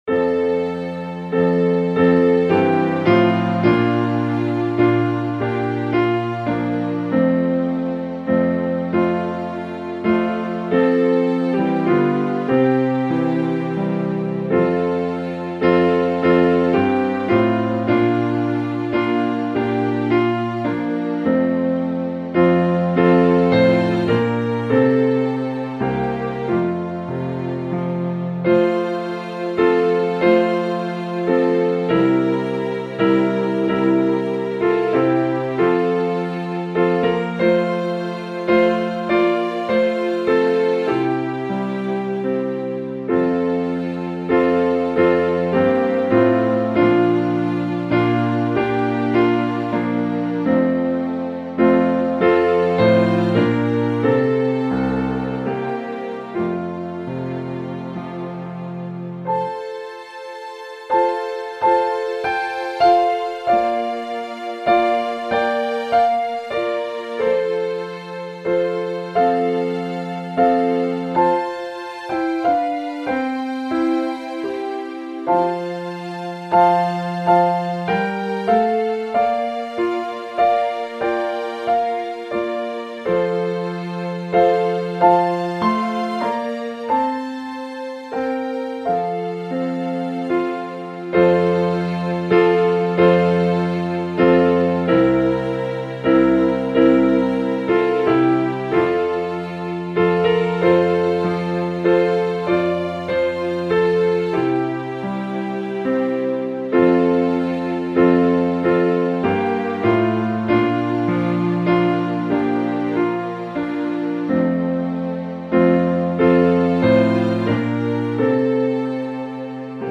AWR - Instrumental Music